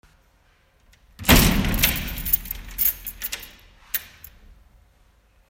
Przyporządkujcie numerki do właściwych dźwięków: łamanie hostii, przewracanie stron w Mszale, zamykanie drzwi w kościele, dzwonki, wlewanie wody do kielicha, otwieranie drzwi do konfesjonału, gong, machanie kadzidłem.